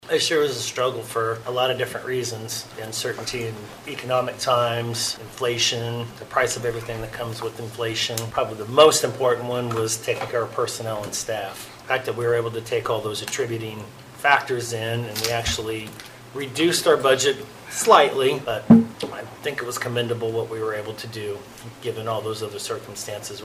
The county budget was republished for the proper length of time prior to the hearing, and remained the same, with $30,426,513 to be levied in property tax with a mill levy of 42.211. Commissioner John Ford commended staff for working to keep the budget as low as possible.